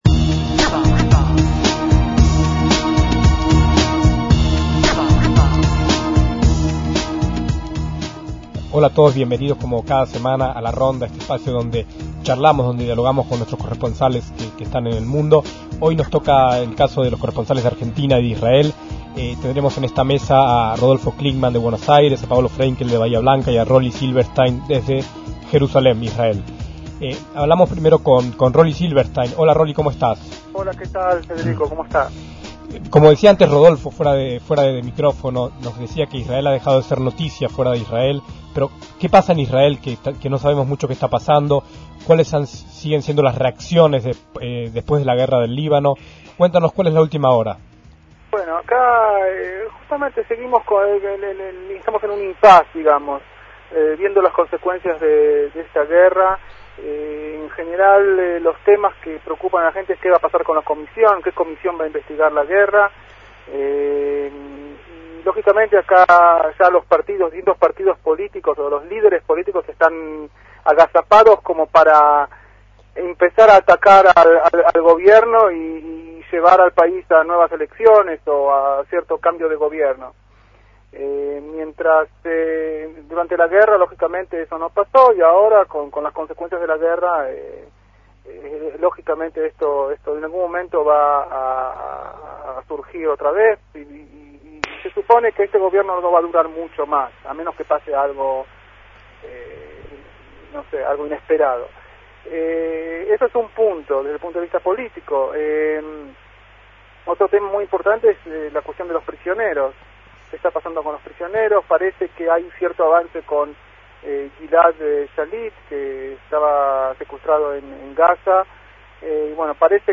En esta ronda de corresponsales de Radio Sefarad en el mundo hace 19 años participaron